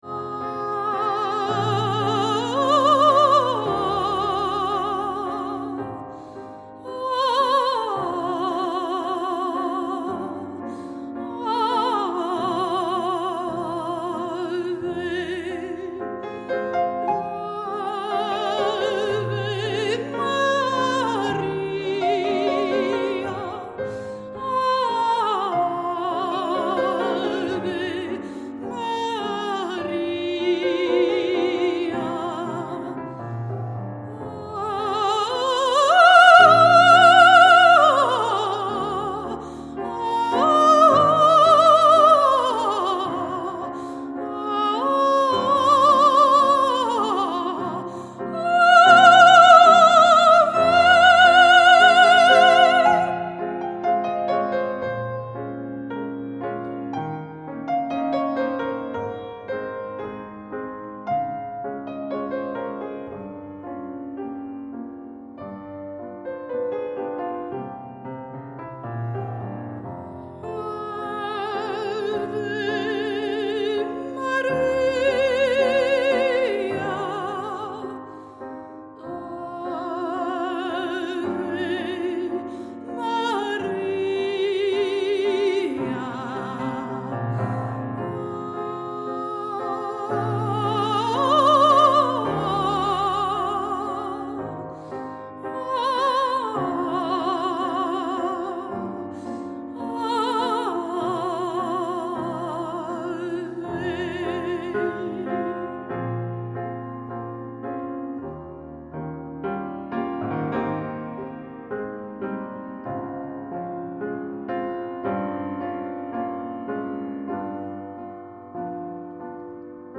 sopraan voor klassieke en lichte muziek
ave-maria_sopraan-1.mp3